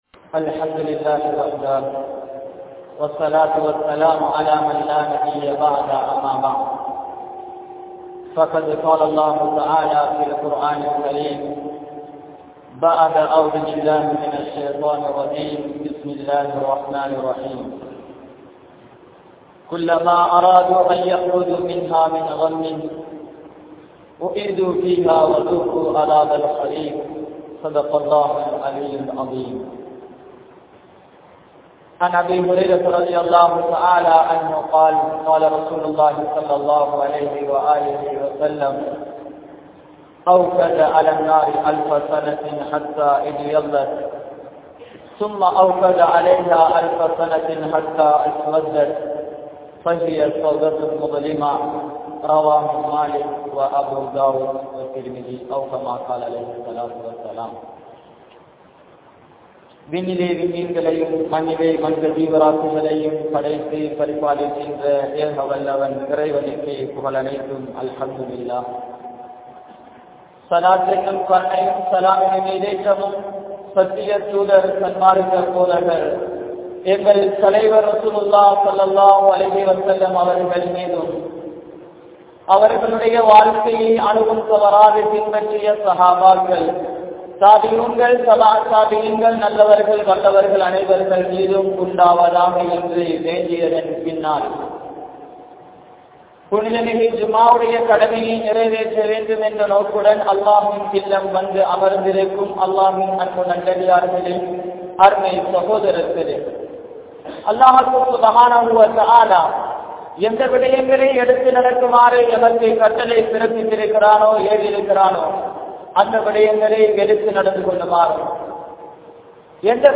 Naraha Vaathien Avala Nilai (நரகவாதியின் அவலநிலை) | Audio Bayans | All Ceylon Muslim Youth Community | Addalaichenai
Saliheen Jumua Masjidh